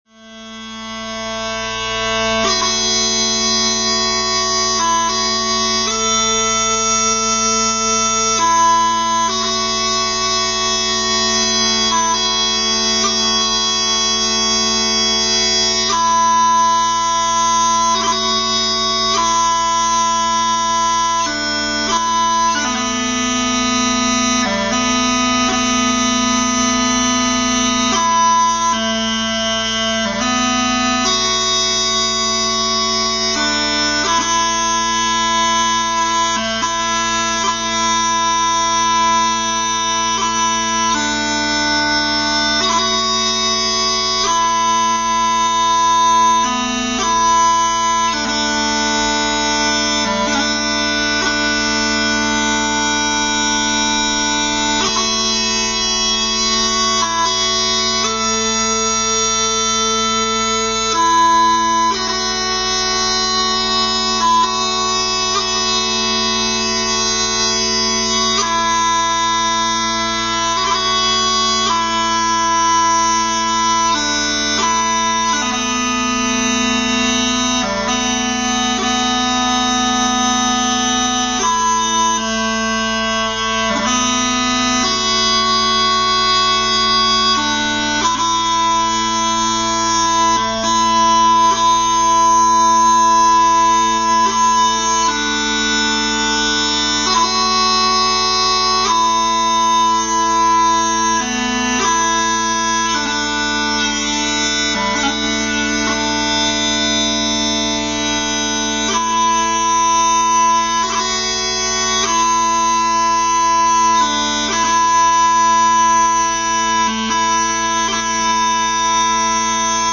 The internal design both of the stock and of the chanter has been modified in a way which now gives the chanter much more resonance and projection.
Here are some tunes played on them:
The ground of the 'Lament for the Children', by Patrick Mor MacCrimmon, one of the most evocative and melodic tunes ever written, played this time using only the bass and tenor drones, (1462Kb)